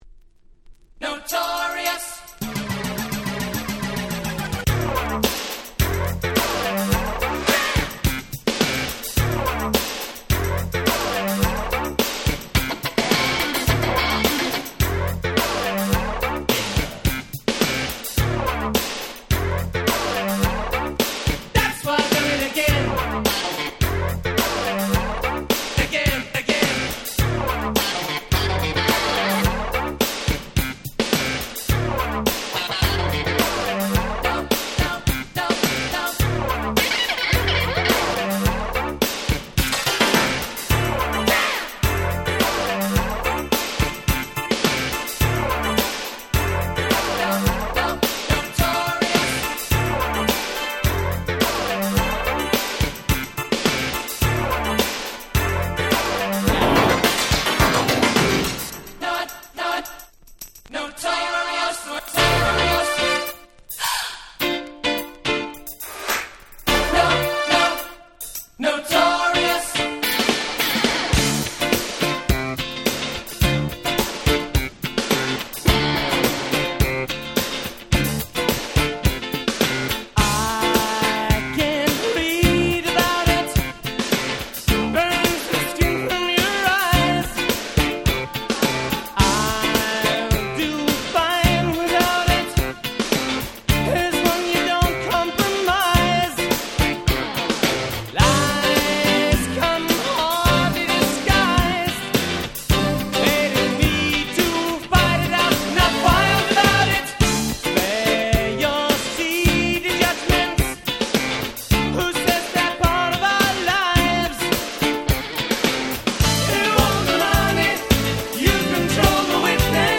86' Smash Hit Rock / Pops !!